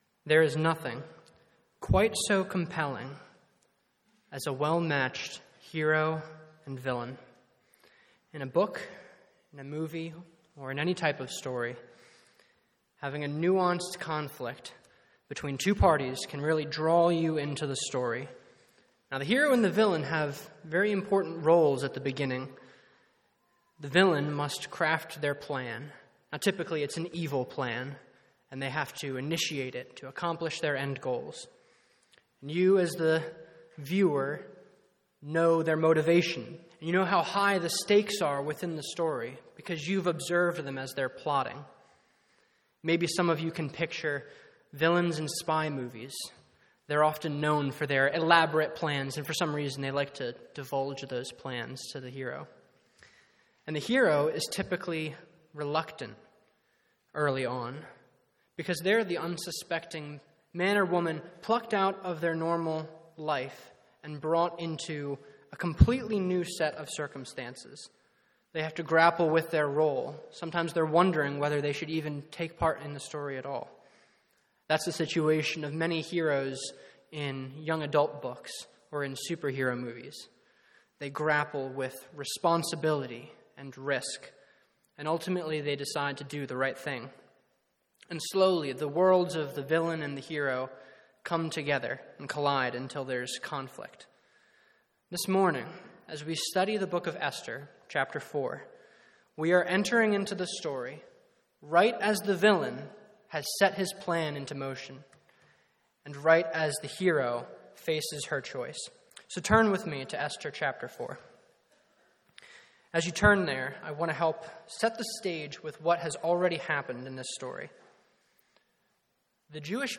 Esther — Audio Sermons — Brick Lane Community Church